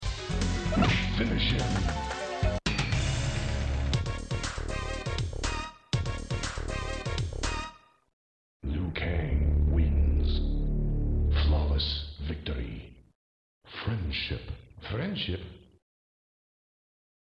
Mortal Kombat II 1993 Arcade Sound Effects Free Download